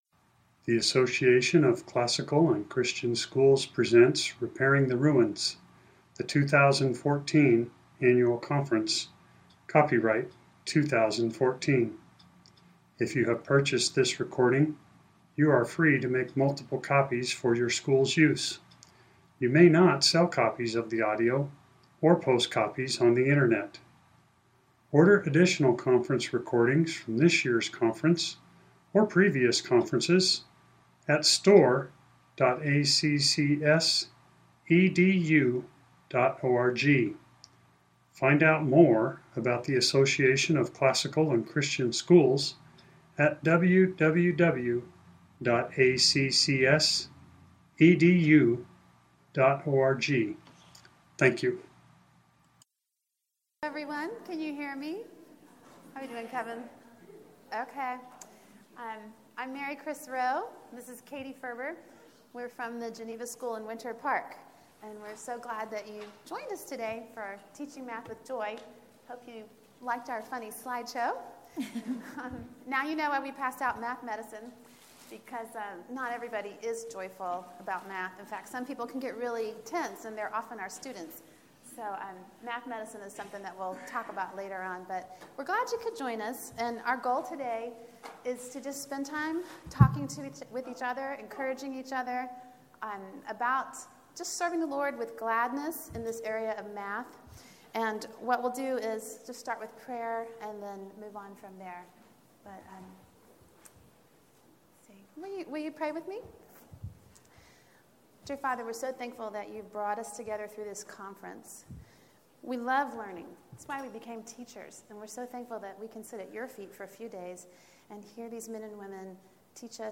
2014 Workshop Talk | 0:58:52 | 7-12, Math
The Association of Classical & Christian Schools presents Repairing the Ruins, the ACCS annual conference, copyright ACCS.